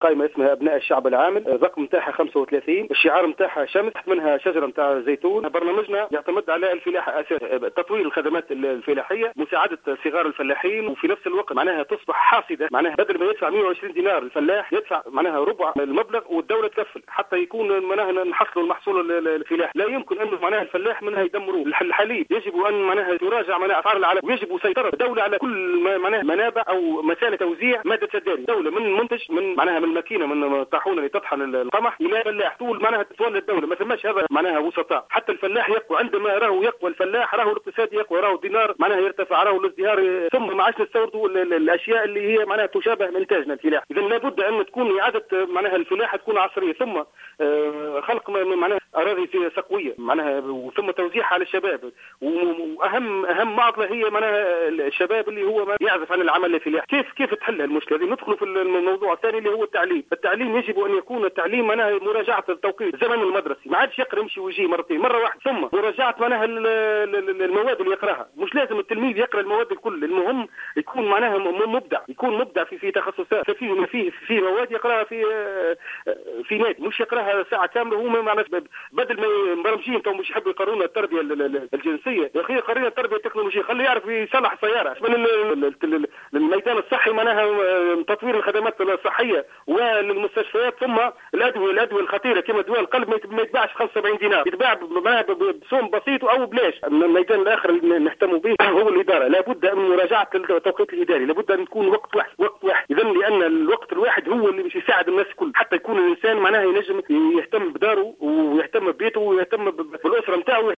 وأضاف في تصريح اليوم لـ"الجوهرة أف أم" أنهم سيعملون أيضا على مراجعة الزمن المدرسي والمواد التي تُدرس إضافة إلى تطوير الخدمات الصحية و إعادة النظر في التوقيت الاداري...